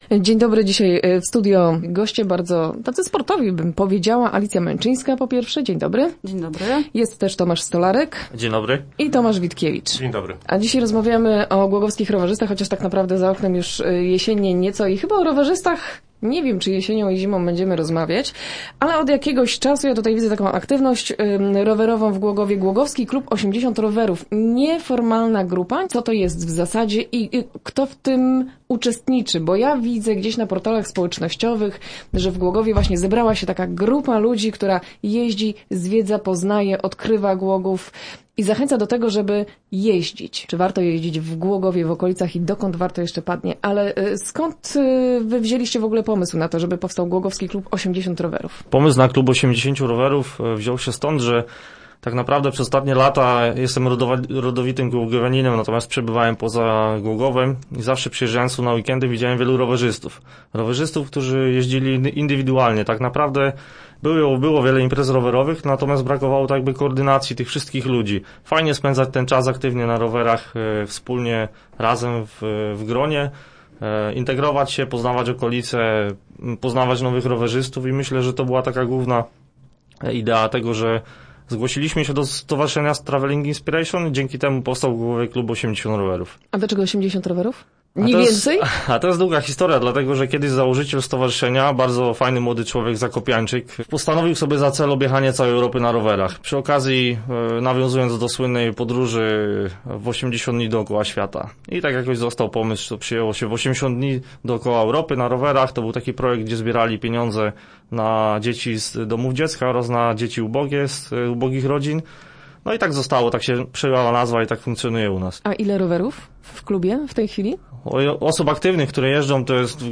Start arrow Rozmowy Elki arrow Rowerowa integracja Głogowa